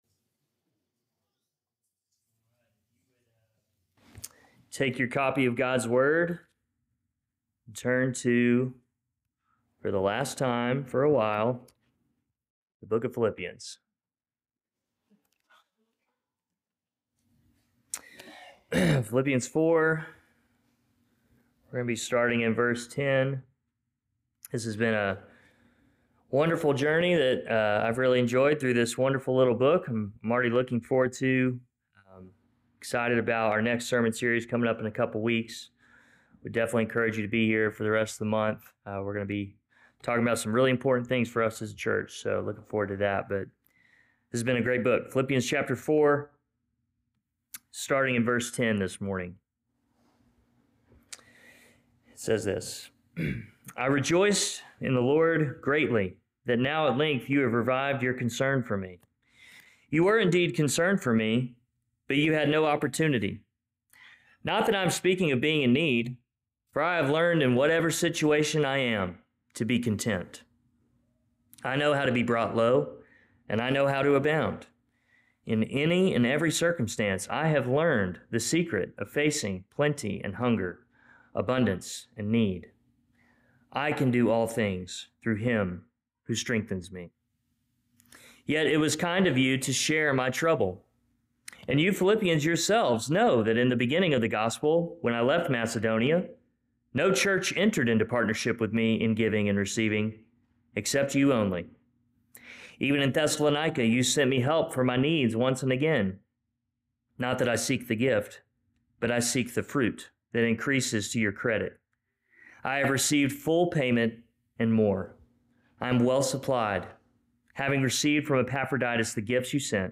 Philippians: Rejoicing In Christ Current Sermon